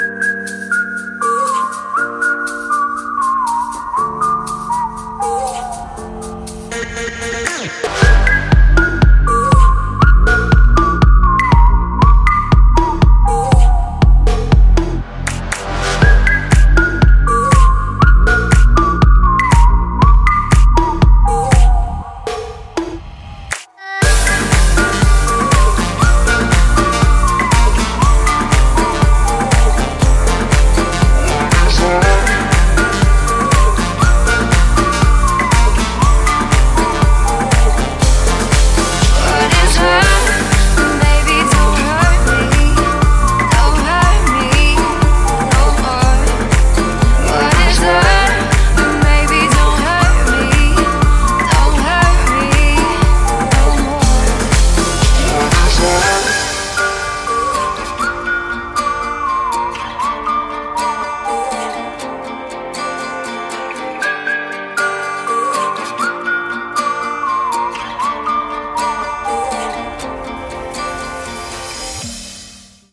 • Качество: 320, Stereo
красивые
женский вокал
мелодичные
спокойные